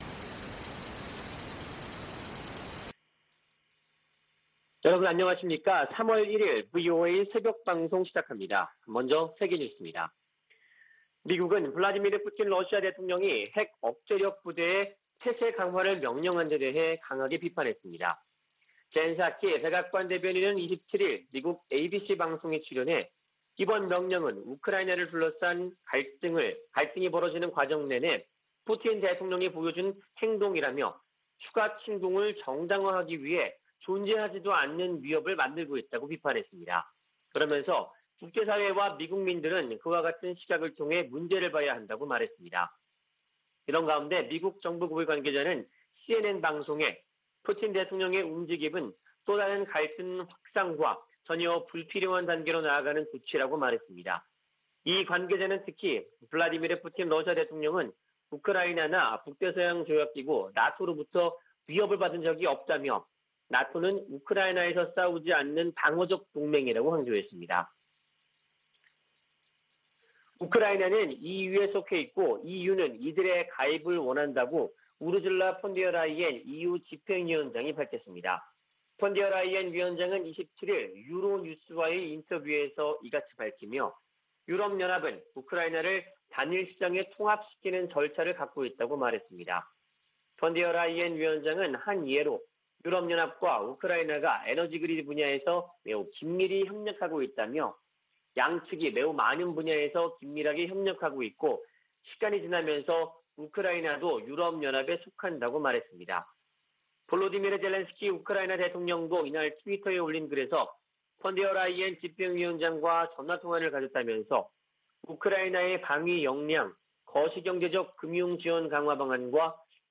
VOA 한국어 '출발 뉴스 쇼', 2022년 3월 1일 방송입니다. 북한은 27일 발사한 준중거리 탄도미사일이 정찰위성에 쓰일 카메라 성능을 점검하기 위한 것이었다고 밝혔습니다. 미 국무부는 북한의 탄도미사일 시험 발사 재개를 규탄하고 도발 중단을 촉구했습니다. 미한일 외교∙안보 고위 당국자들이 전화협의를 갖고 북한의 행동을 규탄하면서 3국 공조의 중요성을 거듭 강조했습니다.